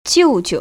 [jiù‧jiu] 지우지우  ▶